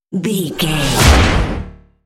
Dramatic creature hit trailer
Sound Effects
Atonal
heavy
intense
dark
aggressive
hits